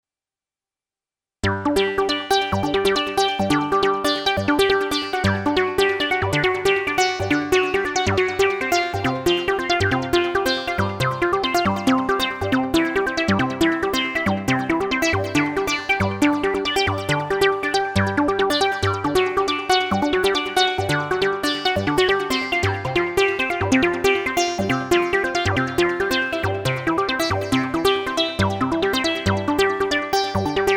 Il primo (XioSynth01.mp3) è un classico utilizzo dell’arpeggiatore; il suono usufruisce a pieno della pulizia del filtro risonante che da apertissimo va a chiudersi rapidamente per enfatizzare la brillantezza dell’attacco.